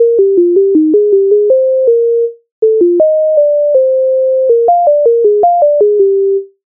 MIDI файл завантажено в тональності Es-dur